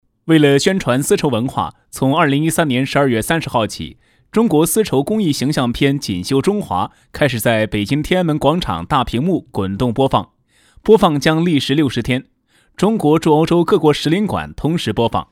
Anuncio en chino
A continuación le mostramos una demostración en chino que sirve de ejemplo como presentación de un producto de seda, este anuncio es narrado por voz masculina.
Locutor-varón-chino-27.mp3